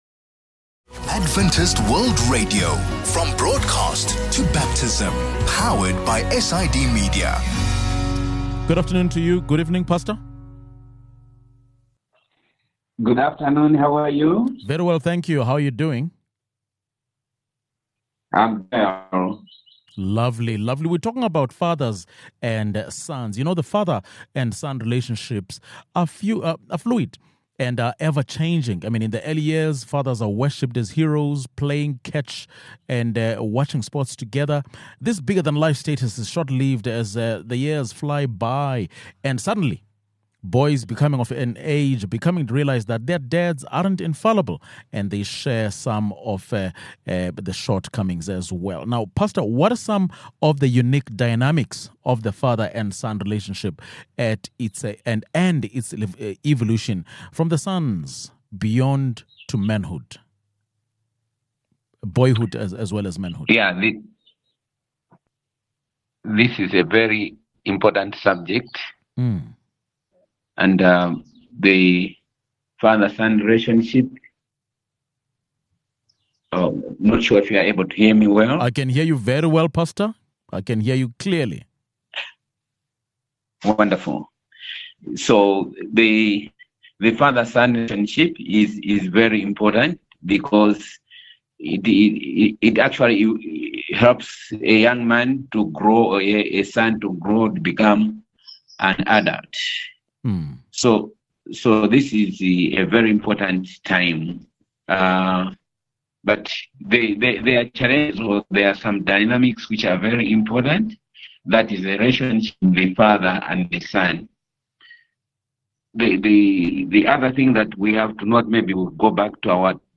This month, we celebrate fathers, starting with this conversation about the father/son relationship dynamics.